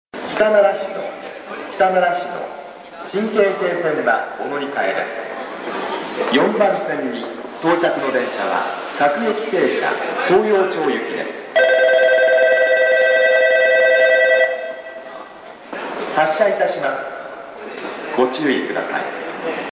駅放送
到着発車 響きやすい 接近放送は東葉快速の放送です。到着発車放送は聞き辛いですがご了承を。